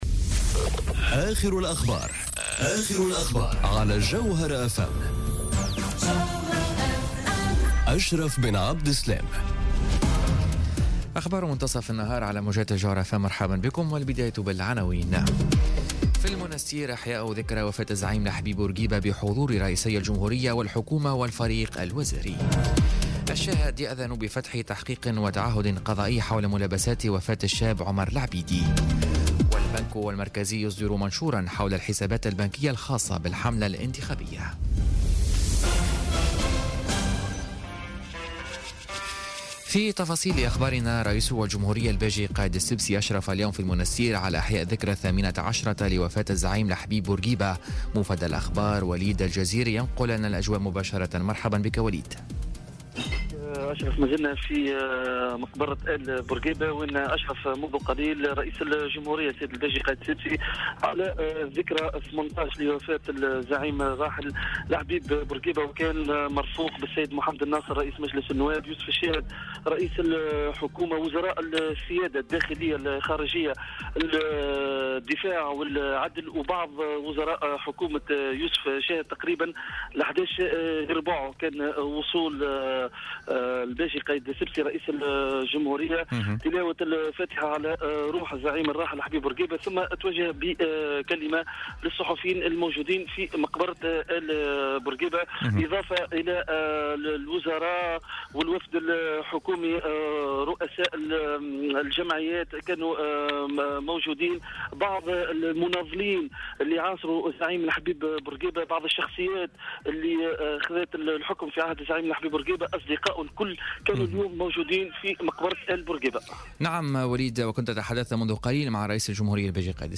نشرة أخبار منتصف النهار ليوم الجمعة 6 أفريل 2018